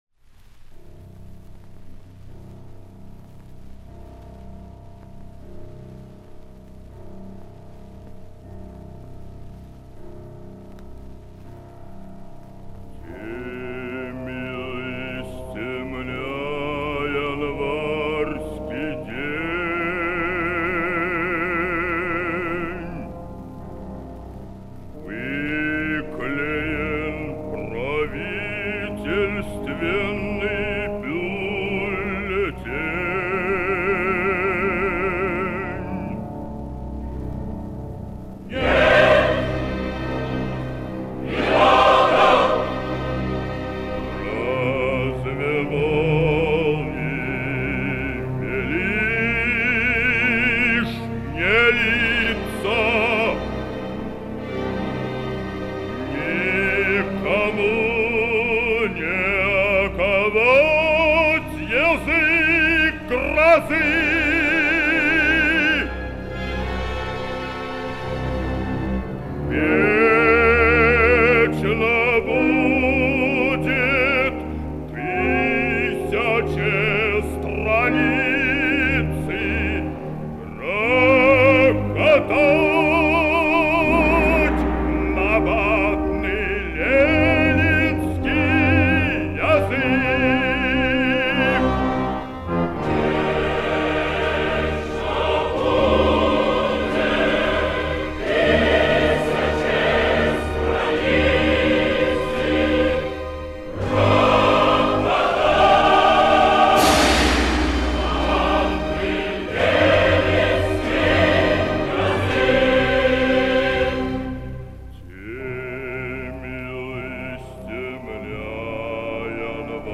Малоизвестная теперь кантата гениального композитора.
Запись 1960-х гг.